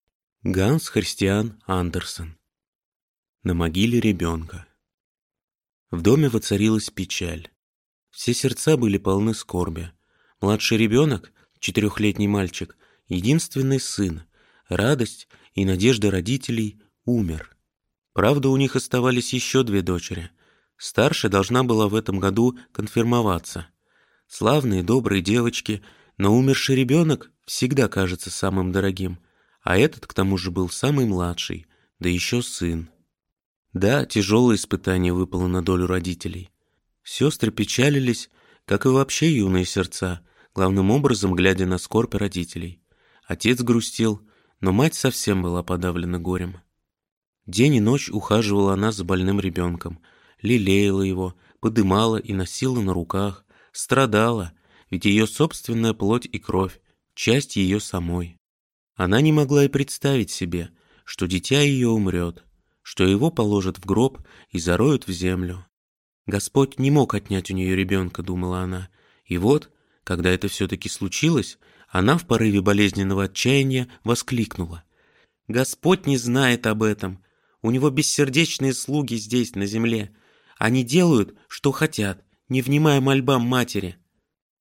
Аудиокнига На могиле ребёнка | Библиотека аудиокниг